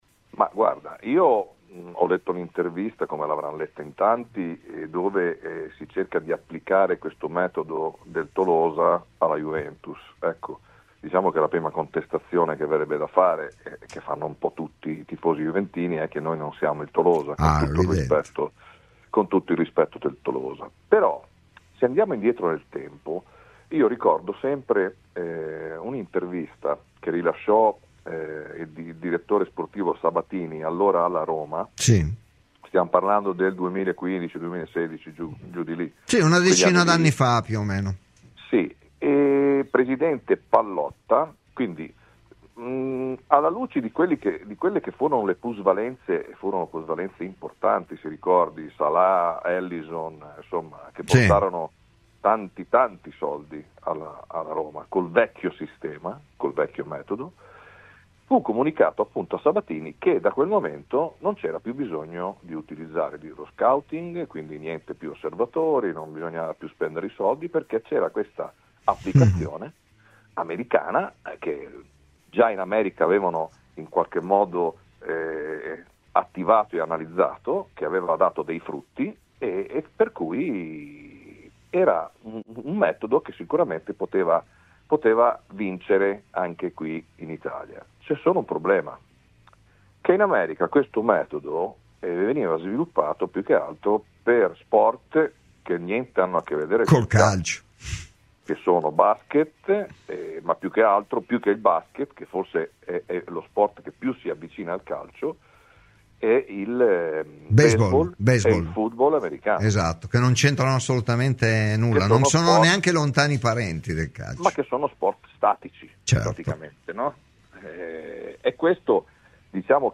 ai microfoni di Radio Bianconera